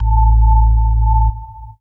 54_28_organ-A.wav